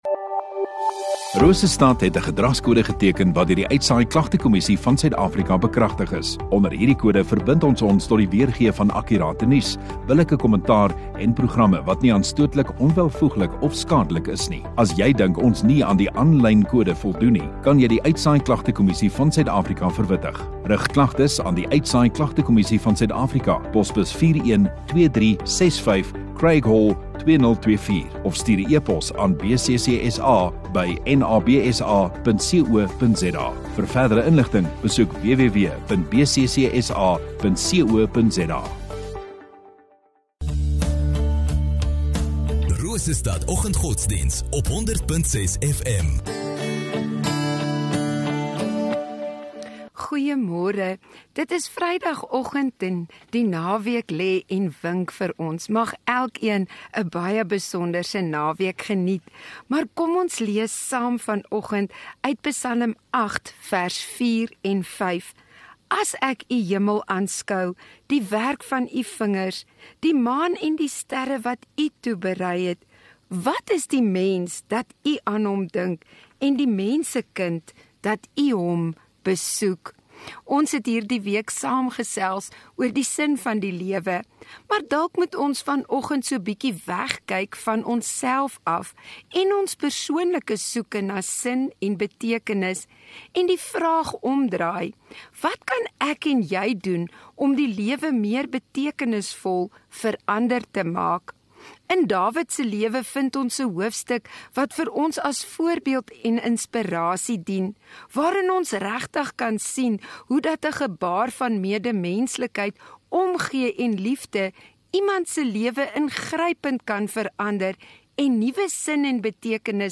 13 Mar Vrydag Oggenddiens